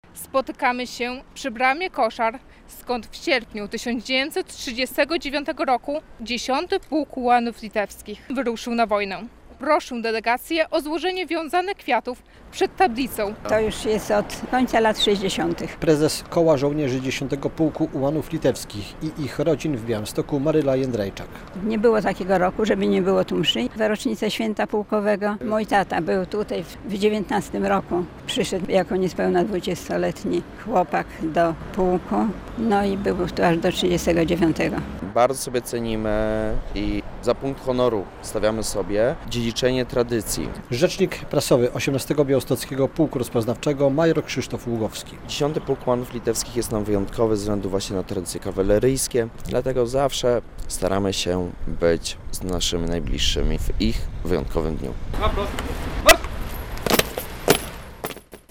Potomkowie ułanów, przedstawiciele władz oraz wojskowi uczcili w Białymstoku pamięć 10. Pułku Ułanów Litewskich.